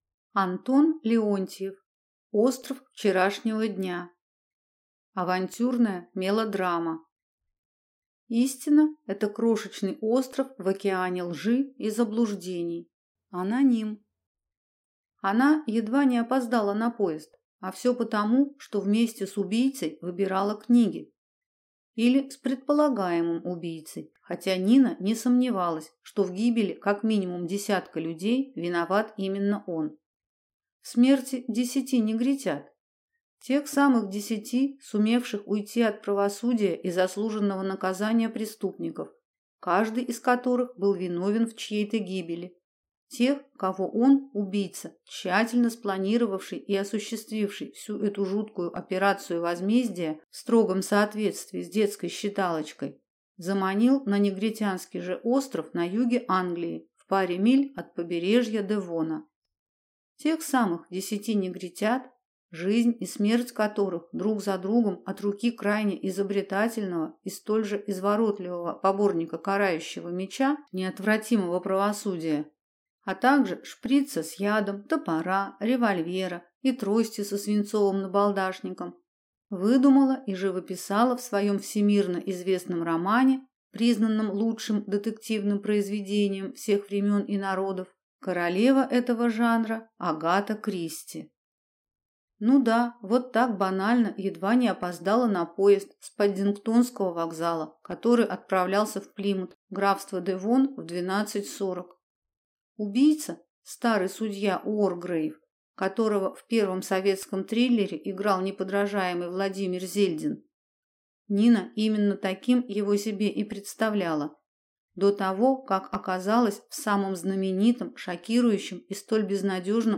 Аудиокнига Остров вчерашнего дня | Библиотека аудиокниг